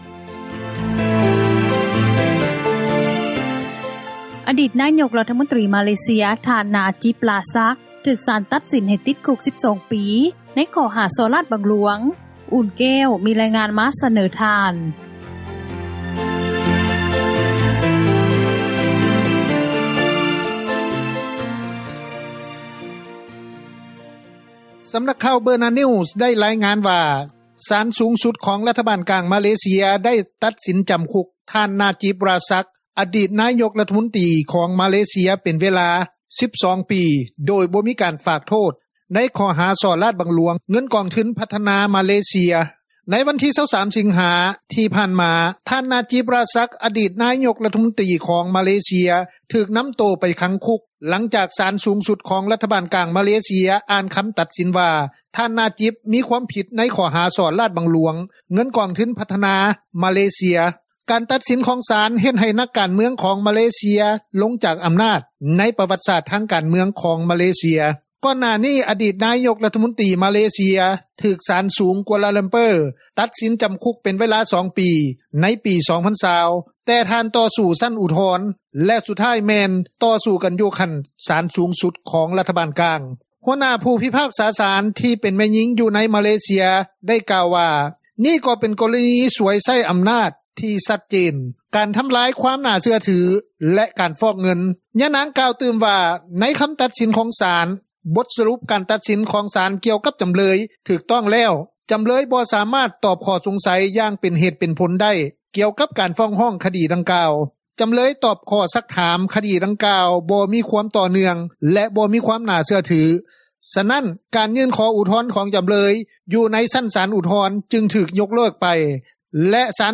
ນັກທຸຣະກິຈ ທ່ານນຶ່ງ ໃຫ້ສັມພາດຕໍ່ວິທຍຸເອເຊັຽເສຣີ ວ່າ: ເປັນຈັ່ງຊີ້..................